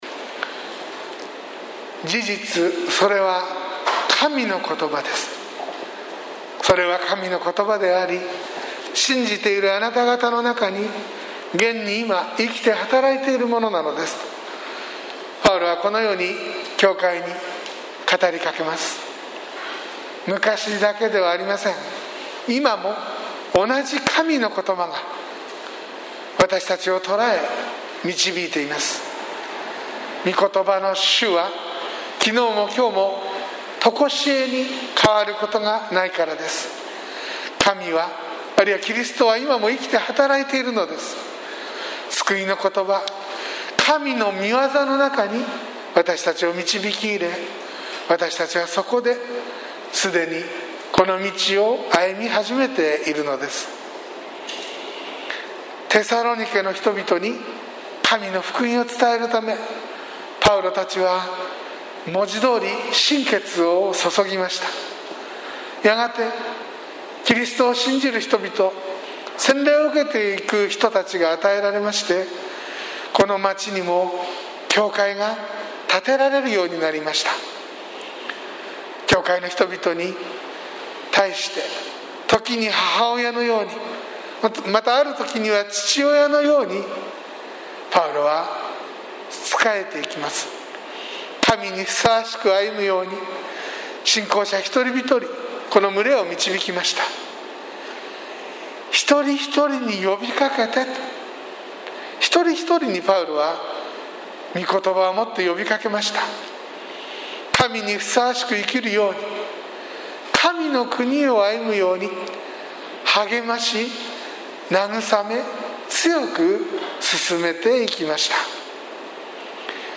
sermon-2020-07-05